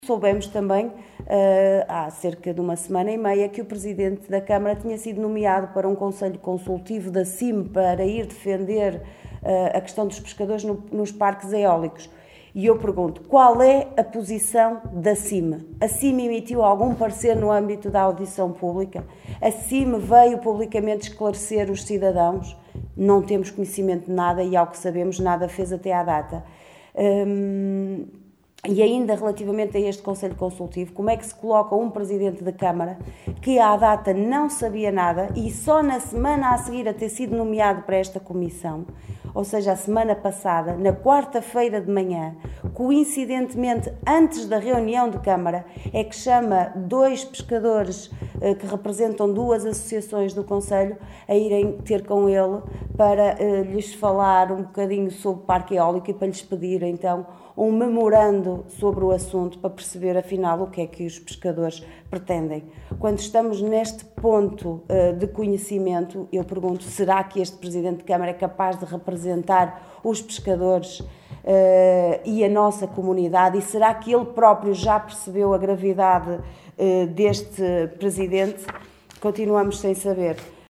Liliana Silva na Conferência de imprensa desta manhã convocada pela OCP.